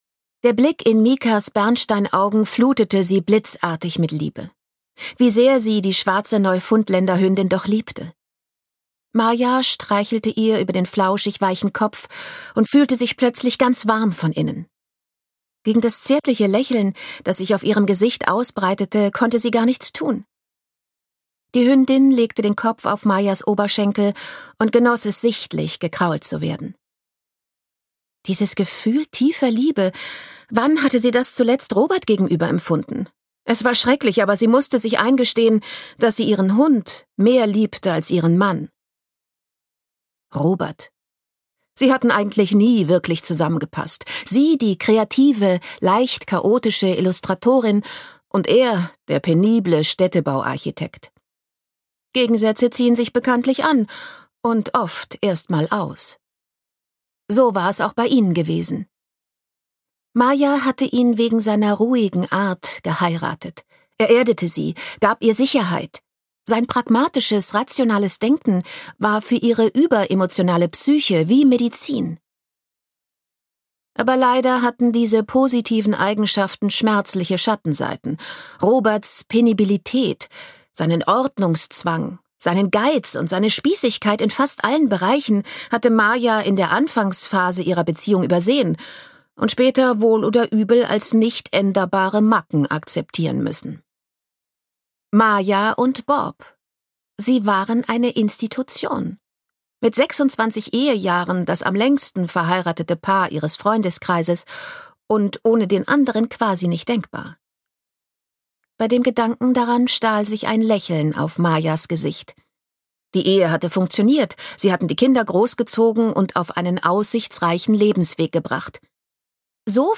Ein Glückshörbuch | Eine hinreißend turbulente Liebesgeschichte auf der Sehnsuchtsinsel Sylt
Gekürzt Autorisierte, d.h. von Autor:innen und / oder Verlagen freigegebene, bearbeitete Fassung.